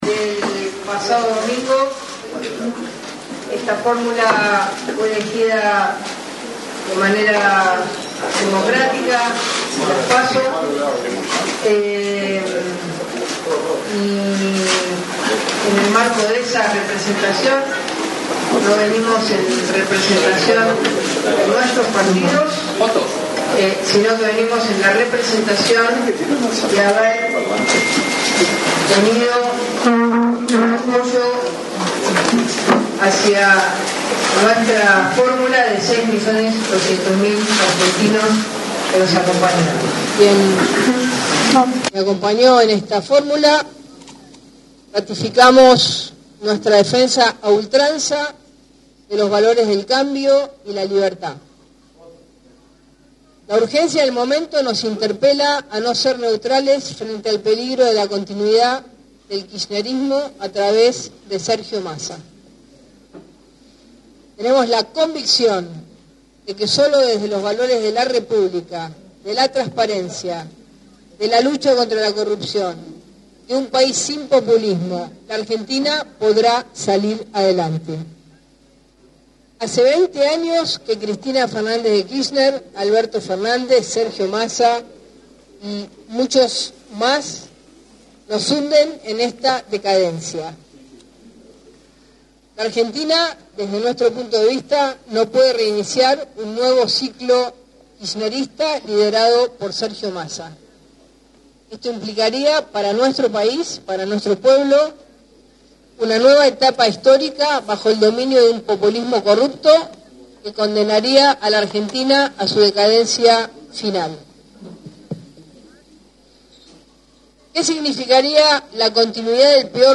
Patricia Bullrich encabezó una conferencia en la cual confirmó que apoyará a Javier Milei, candidato a Presidente por La Libertad Avanza, en el balotaje del próximo 19 de noviembre, en contra del candidato por Unión por la Patria, Sergio Massa.
CONFERENCIA-PATRICIA-BULLRICH-DEL-PLATA.mp3